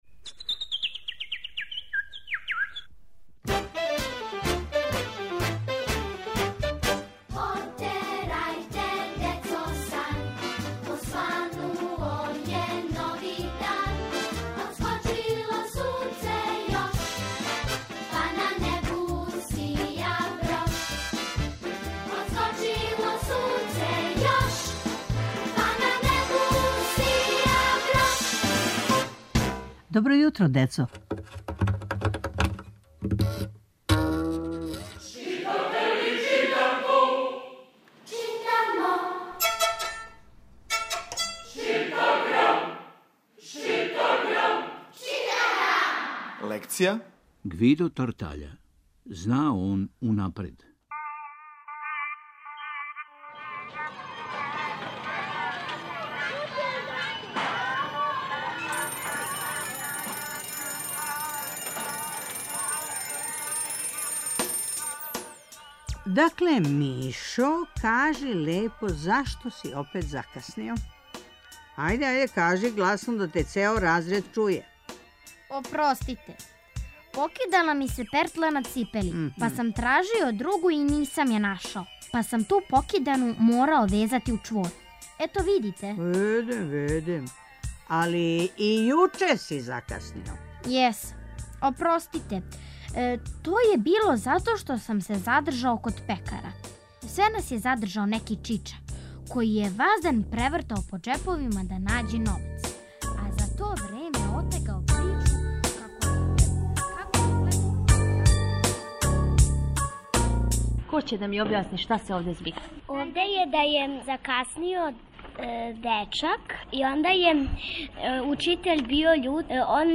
Сваког понедељка у емисији Добро јутро, децо - Читаграм: Читанка за слушање.